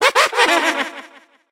evil_gene_vo_02.ogg